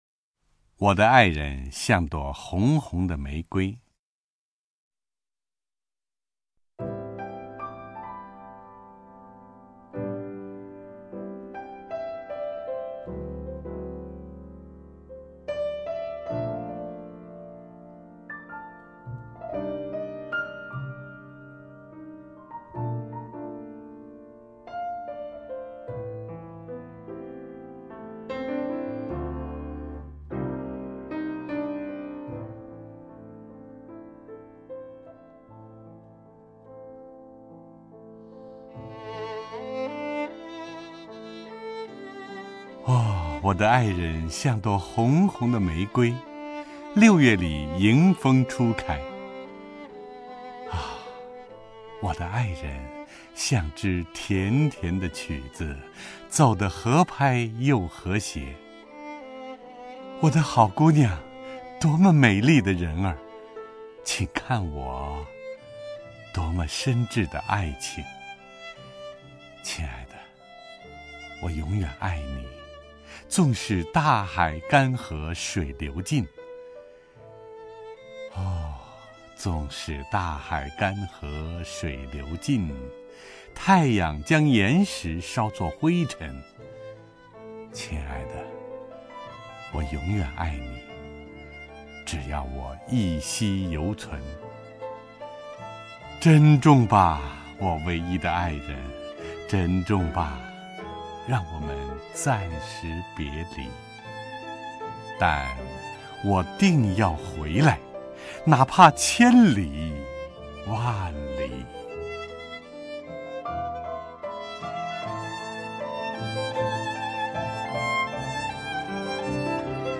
首页 视听 名家朗诵欣赏 陈铎
陈铎朗诵：《我的爱人象朵红红的玫瑰》(（苏格兰）罗伯特·彭斯)